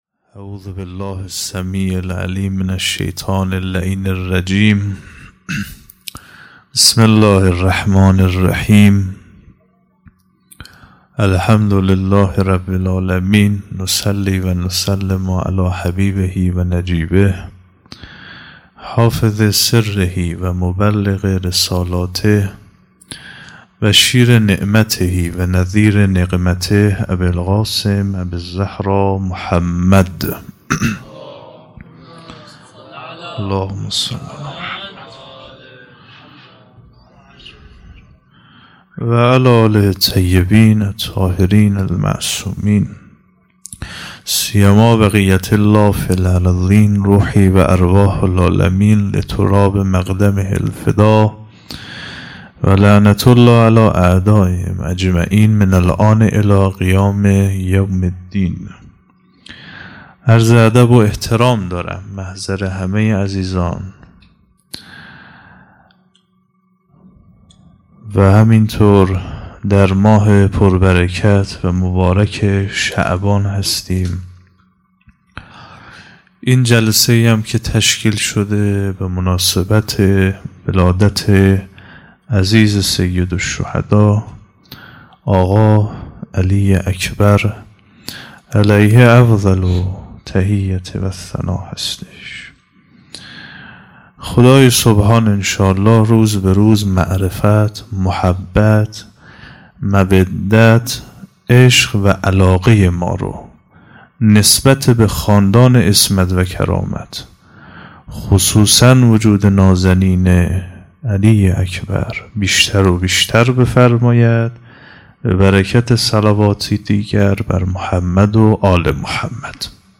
0 0 سخنرانی
جشن میلاد شهزاده علی اکبر علیه السلام